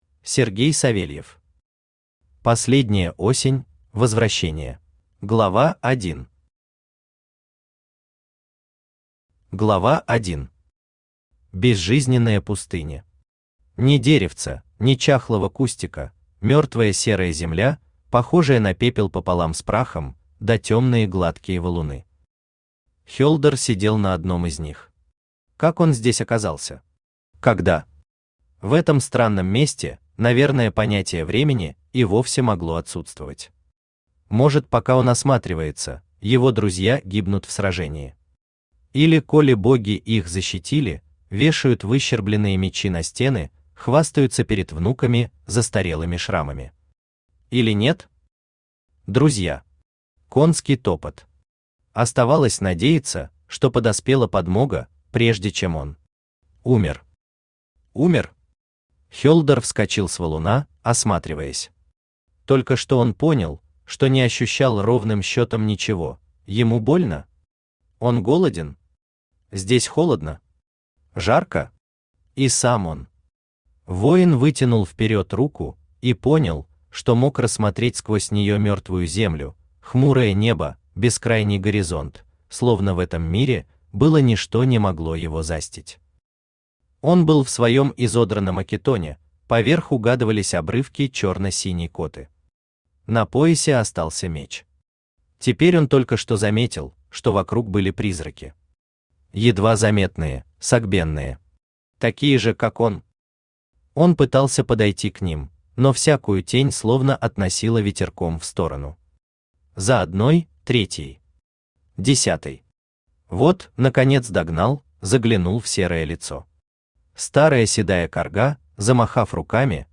Аудиокнига Последняя Осень: Возвращение | Библиотека аудиокниг
Aудиокнига Последняя Осень: Возвращение Автор Сергей Алексеевич Савельев Читает аудиокнигу Авточтец ЛитРес.